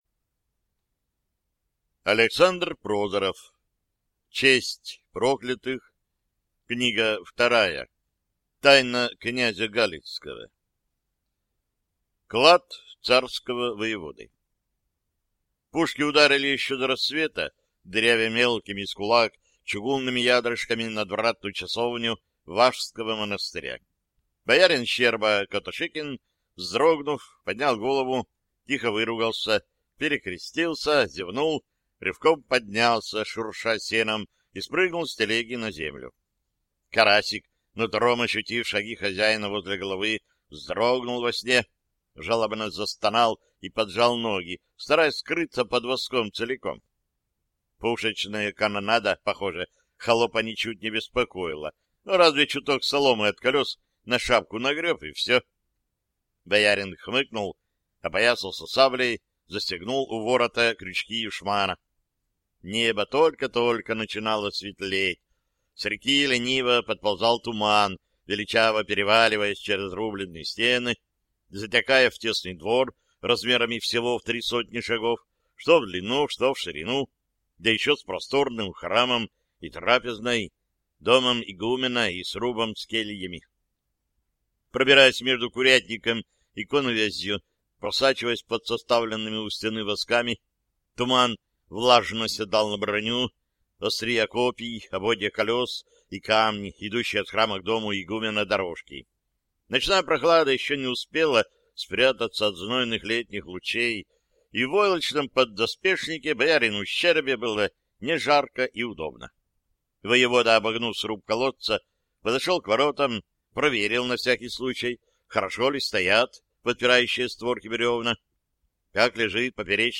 Аудиокнига Тайна князя Галицкого | Библиотека аудиокниг